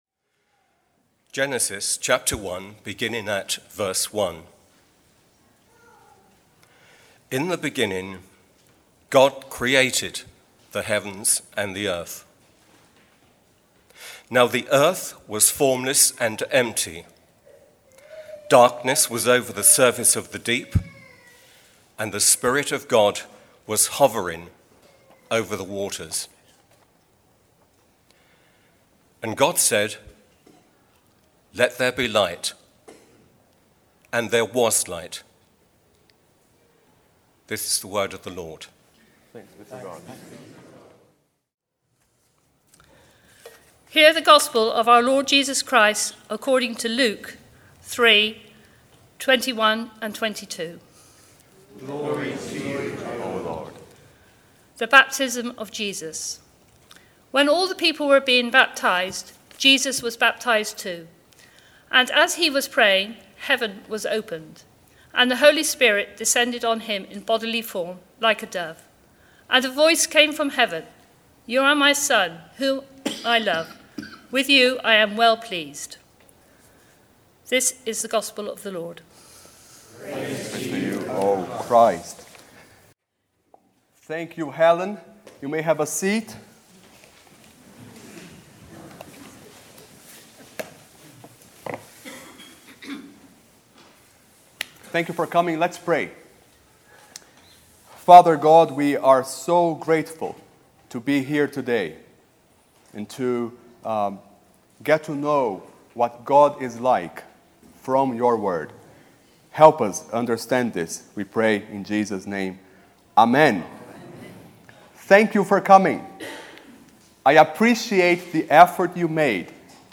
Try Sunday Theme: 3 - Who is God? Sermon